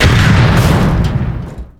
barrel explode03.wav